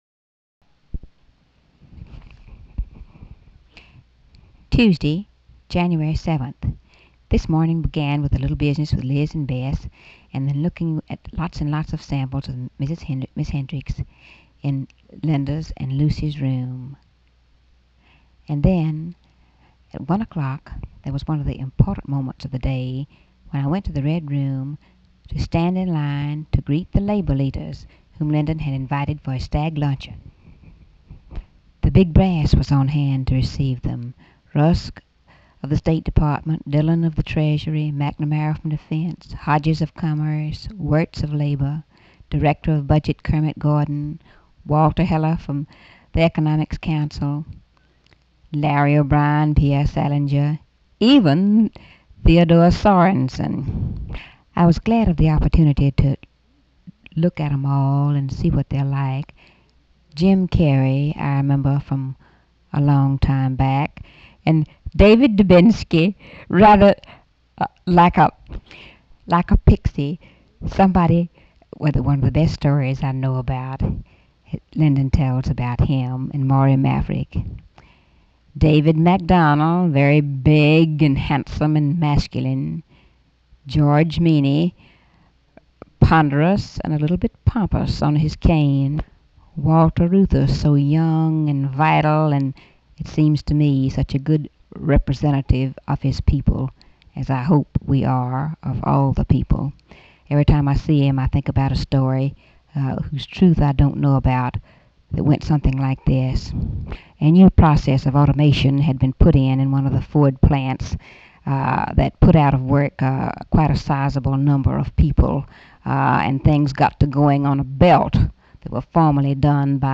Audio diary and annotated transcript, Lady Bird Johnson, 1/7/1964 (Tuesday) | Discover LBJ
White House, Washington, DC